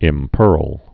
(ĭm-pûrl)